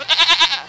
Goat Sound Effect
goat.mp3